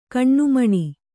♪ kaṇṇumaṇi